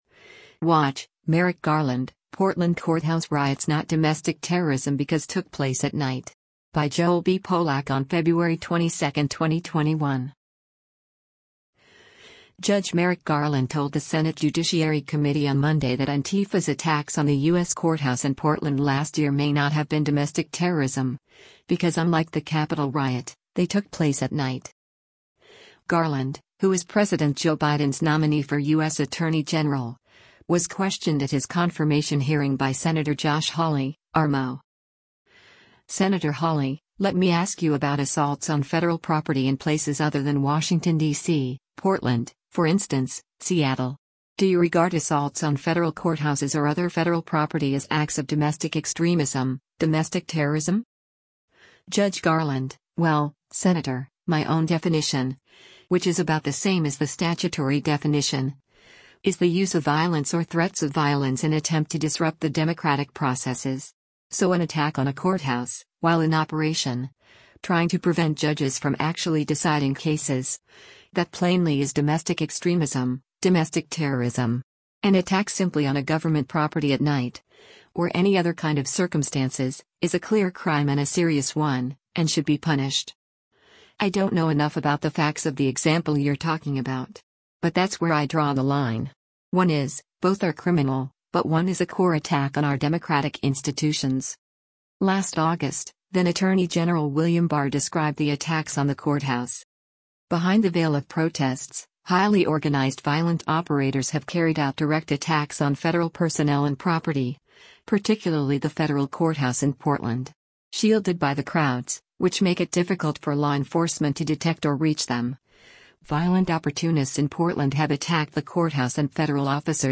Senate Judiciary Committee
Garland, who is President Joe Biden’s nominee for U.S. Attorney General, was questioned at his confirmation hearing by Sen. Josh Hawley (R-MO):